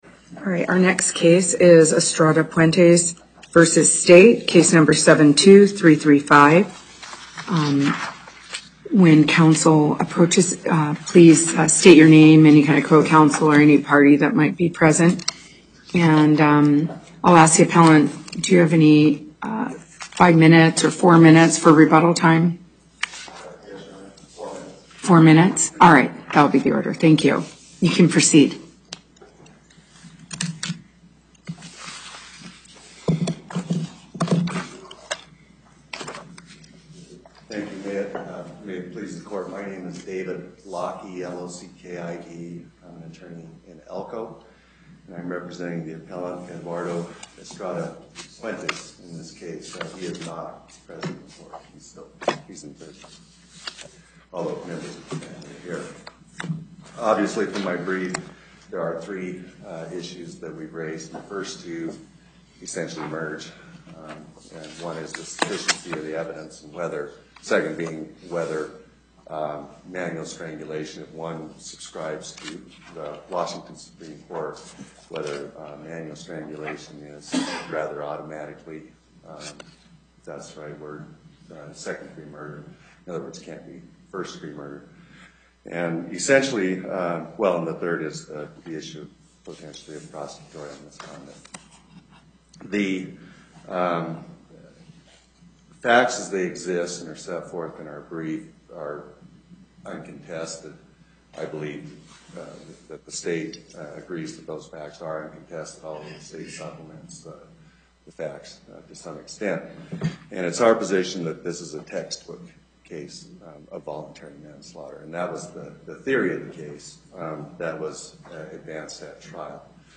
Loading the player Download Recording Docket Number(s): 72335 Date: 03/20/2018 Time: 11:30 A.M. Location: Carson City Before the Court of Appeals, Chief Judge Silver presiding.